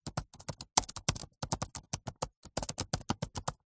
Клавиатура Macbook Pro Apple короткий набор текста 2